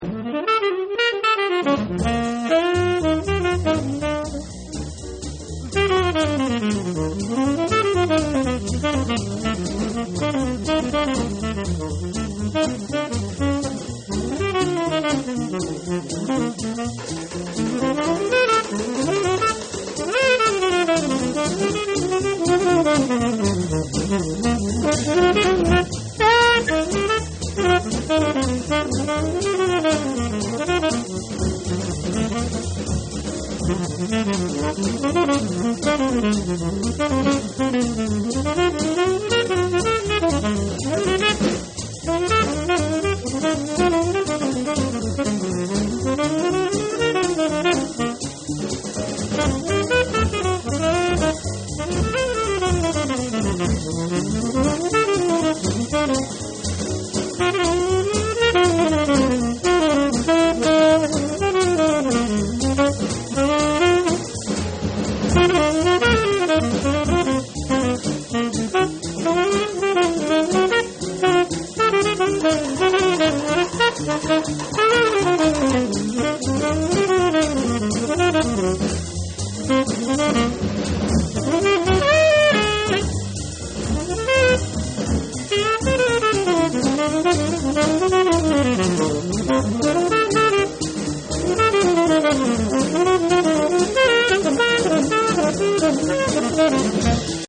quatre saxophonistes tenors
en vitesse normale: